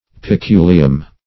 Peculium \Pe*cu"li*um\, n. [L. See Peculiar.]